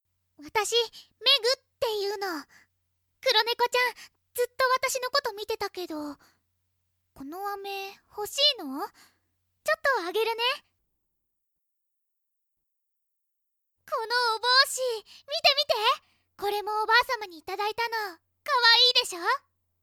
メインキャラクター・サブキャラクターの、イラスト＆簡単な紹介＆サンプルボイスです。
言葉遣いがきれい。